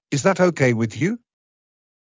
ｲｽﾞ ｻﾞｯﾄ ｵｰｹｲ ｳｨｽﾞ ﾕｰ